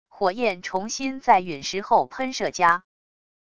火焰重新在陨石后喷射加wav音频